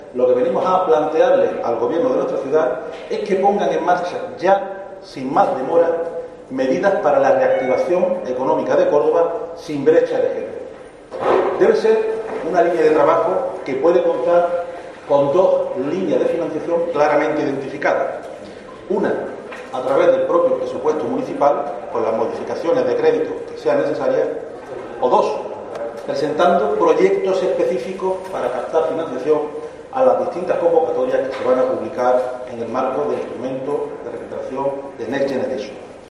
En rueda de prensa, Montoro ha recordado que Córdoba tiene casi 40.000 personas desempleadas y que el paro femenino se ha incrementado un 13,75% desde el inicio de la pandemia.